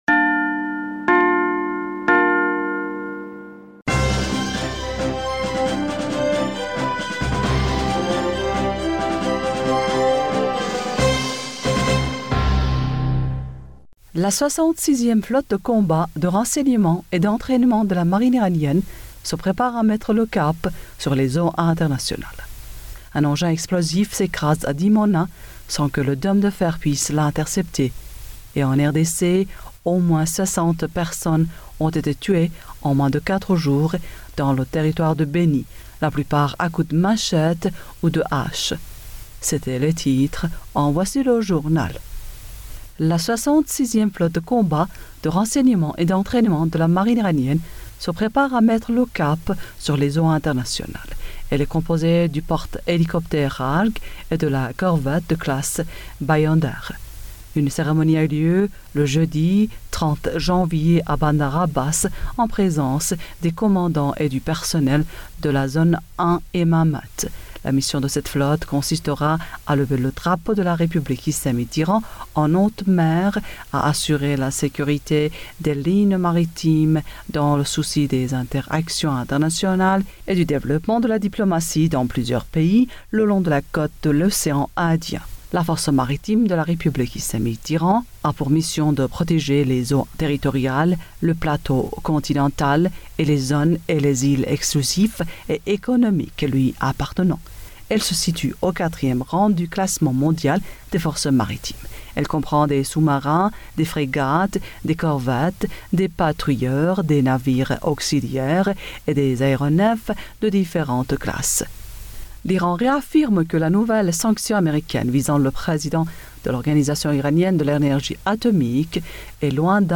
Bulletin d'information du 31 janvier 2020